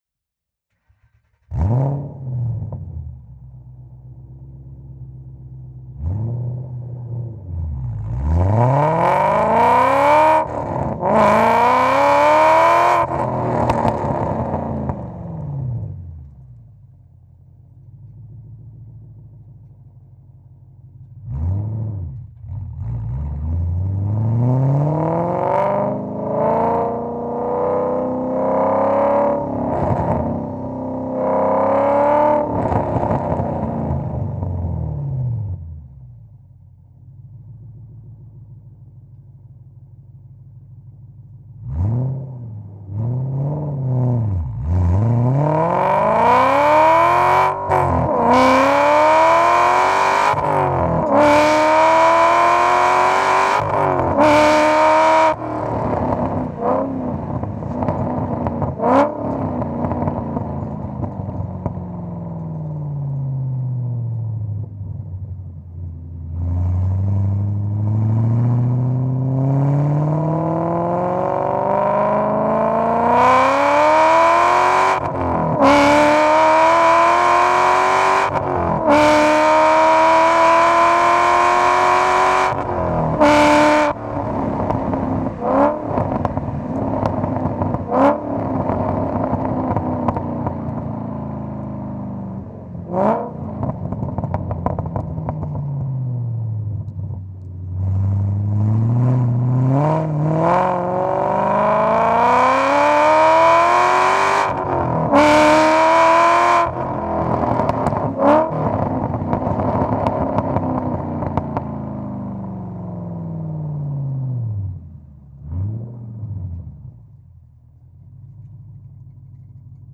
Auspuff Active Sound Einbausätze
Realistischer Klang: Erleben Sie echten Auspuffsound per Knopfdruck, ein- und ausschaltbar nach Belieben.